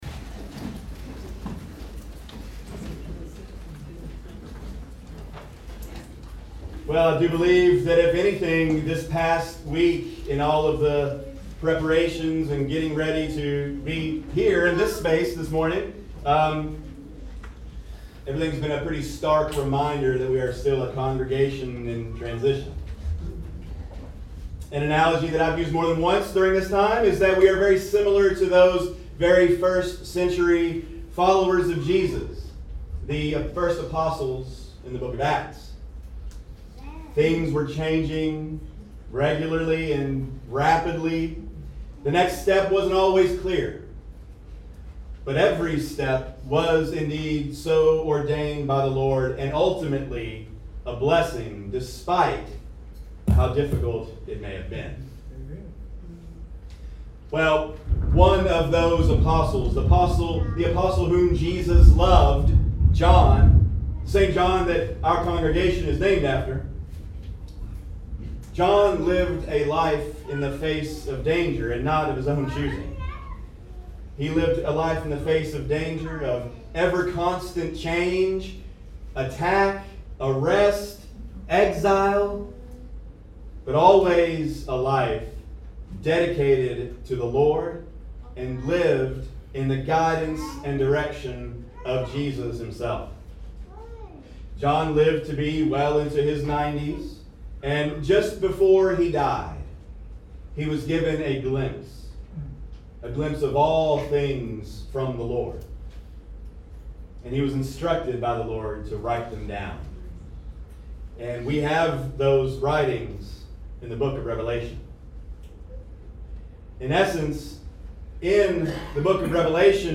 Readings